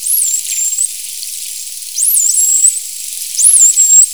dolpfin.wav